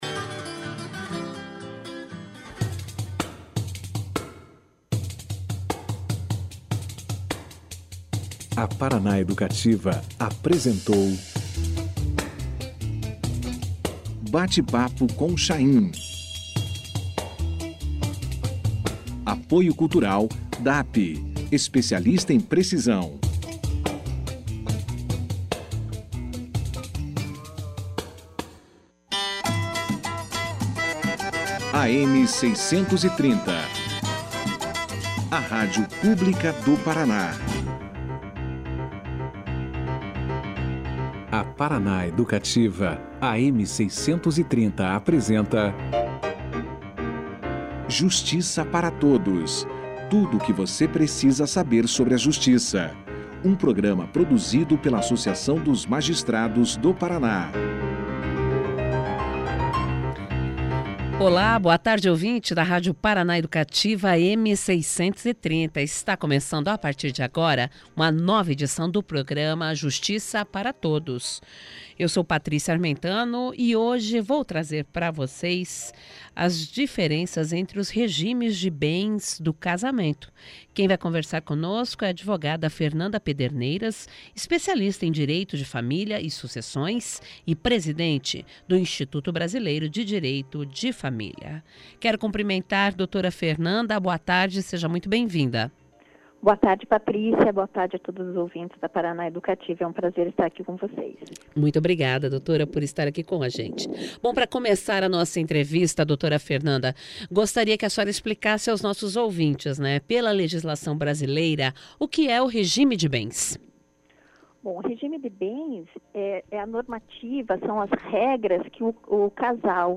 Pela legislação brasileira há 4 regimes de casamento para estabelecer uma união civil. O que muda entre eles é, a separação ou não, do patrimônio. Confira aqui a entrevista na íntegra.